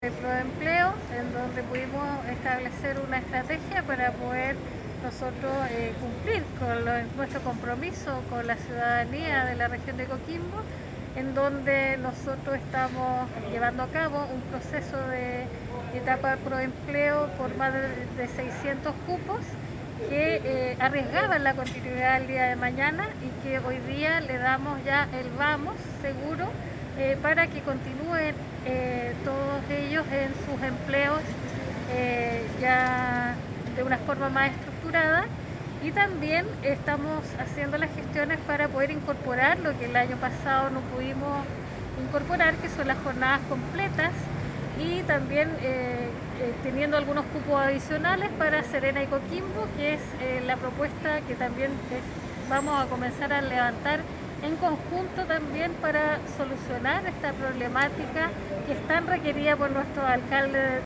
audio-gobernadora.mp3